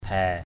/bʱɛ:/ (đg.) kè nhè, lè nhè = radoter, rabâcher.